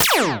sci-fi_weapon_laser_small_03.wav